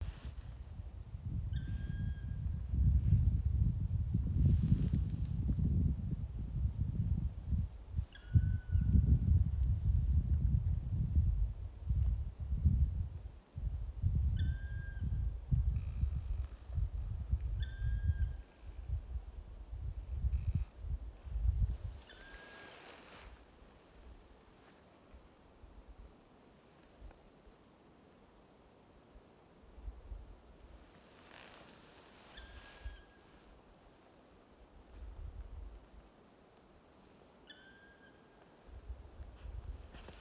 черный дятел, Dryocopus martius
Administratīvā teritorijaCarnikavas novads